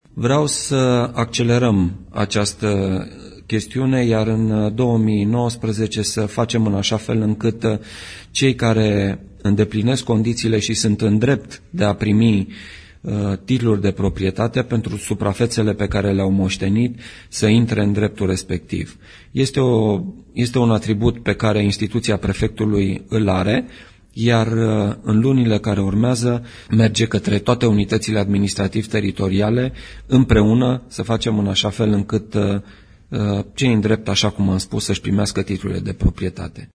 În unele localități, emitere titlurilor de proprietate pentru cei care au moștenit suprafețe este blocată, iar per total, în Județul Brașov, acest demers nu a ajuns nici măcar la jumătate, după cum declară Prefectul Județului Brașov, Marian Rasaliu: